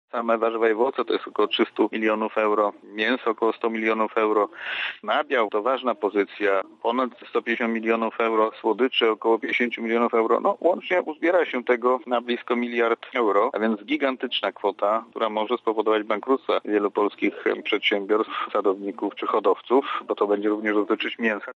Szacunkowo straty wyniosą w granicach jednego miliarda euro – wyjaśnia Janusz Szewczak, główny ekonomista SKOK